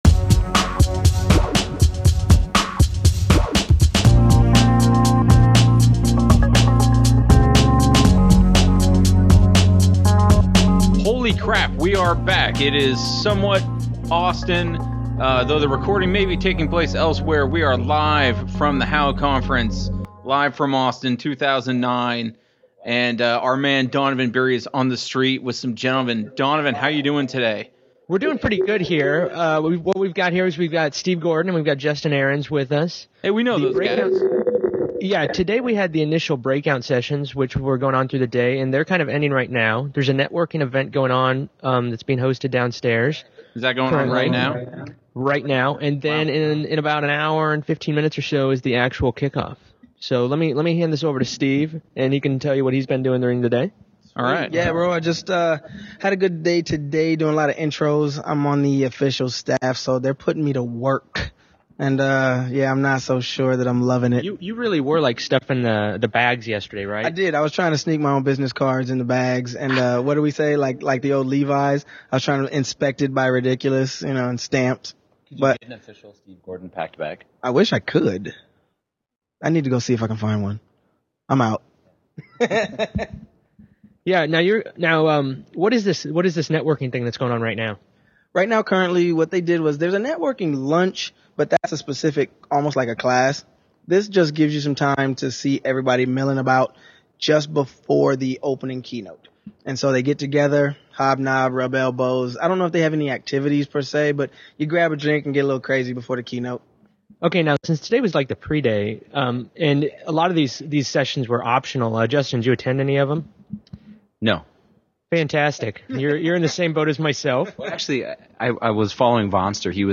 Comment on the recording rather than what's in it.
Doing it right from Austin, Texas, live from the conference center's 4th Floor -- The Reflex Blue Show HOW Conference Special continues!